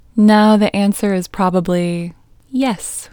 OUT Technique Female English 27